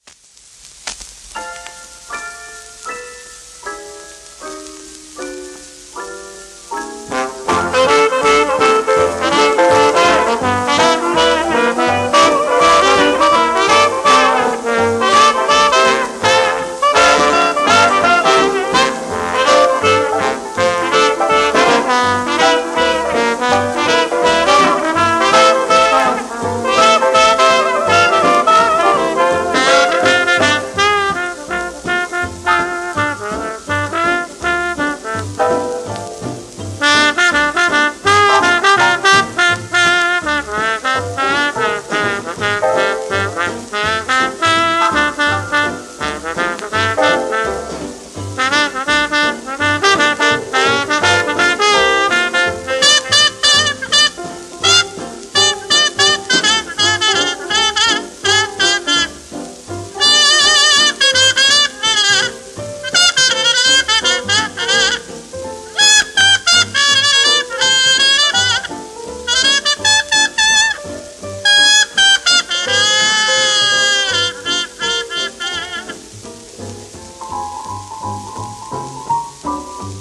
盤質A-/B+ *外周から2cm程度の薄いクラック(ヒビ)あり、小キズ
外周クラック部分を含みレコードプレーヤーで再生しておりますので、音への影響をご確認ください